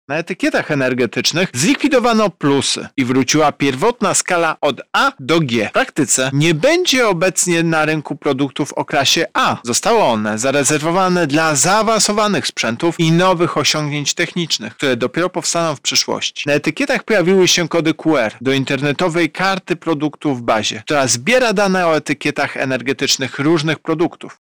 O szczegółach wprowadzanych zmian mówi prezes Urzędu Ochrony Konkurencji i Konsumenta, Tomasz Chróstny